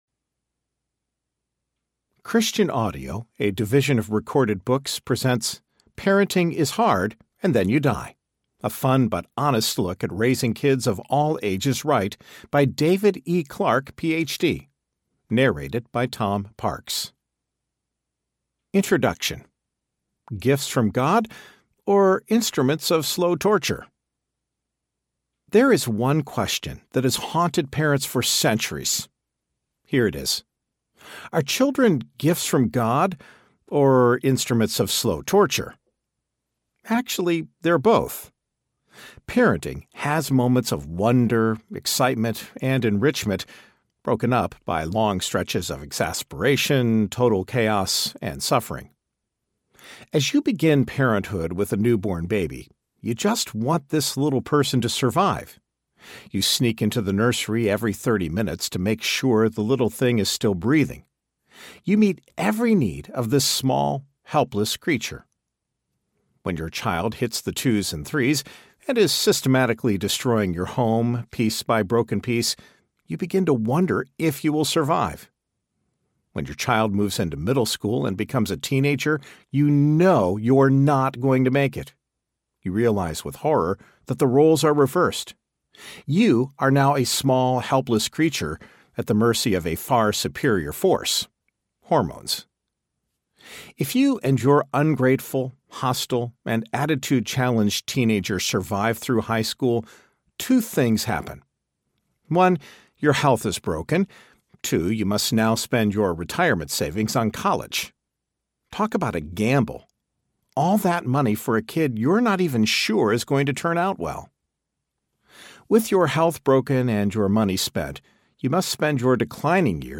Parenting Is Hard and Then You Die Audiobook
6.98 – Unabridged